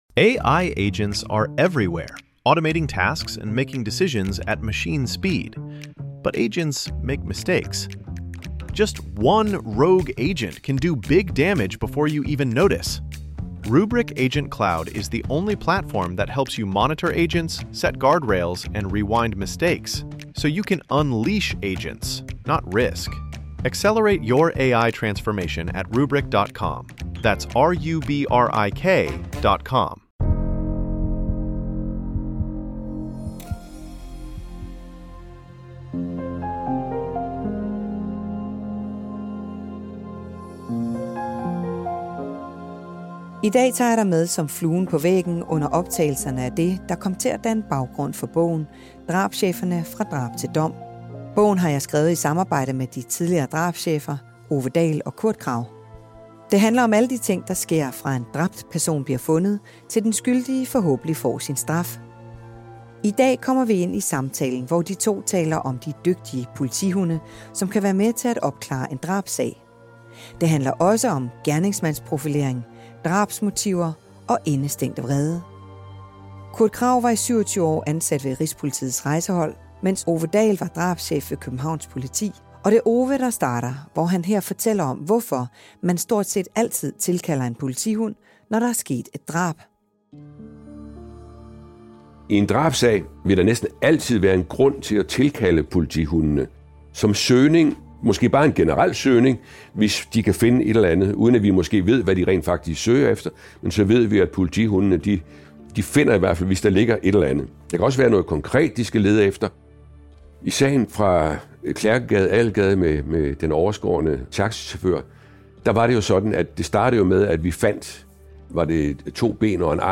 Senere handler det også om gerningsmandsprofilering, drabsmotiver og indestængt vrede. Det hele er en bid af de råbånd, der danner baggrund for bogen Drabscheferne - fra drab til dom, som er udkommet på Politikens Forlag.